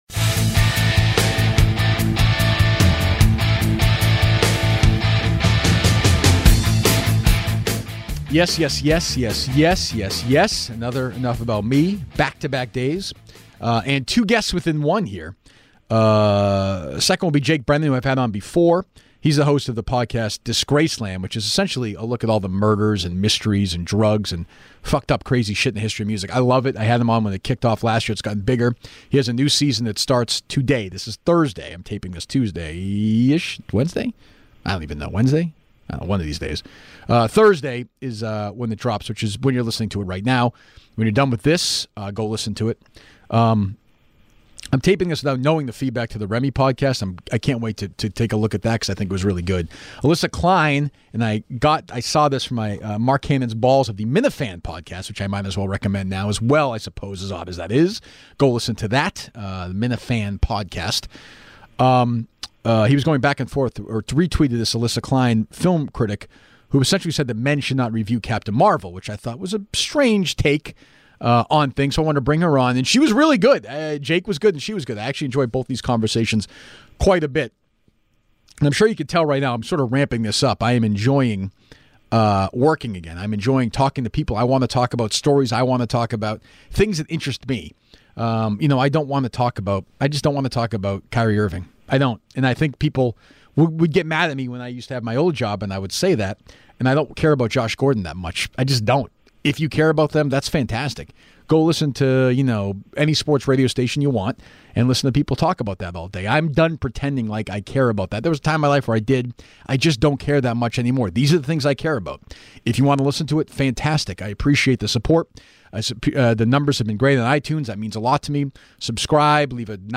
This episode of Enough About Me features two interviews.